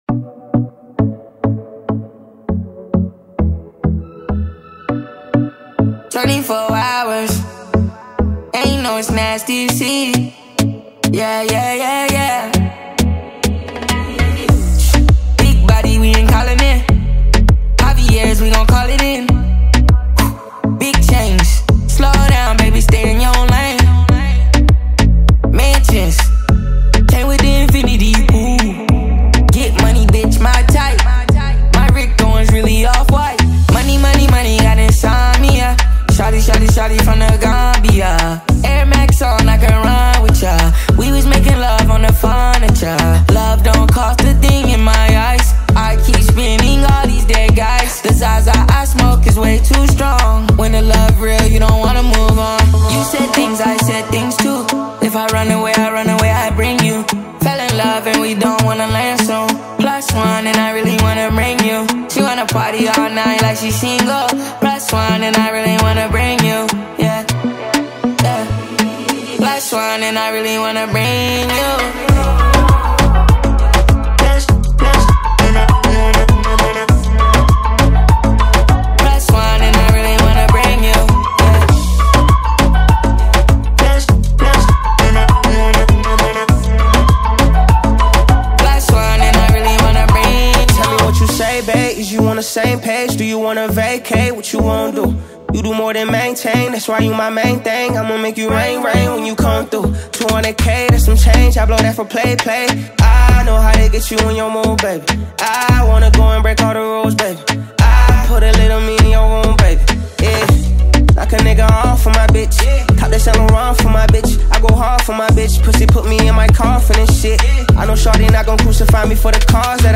American rapper and singer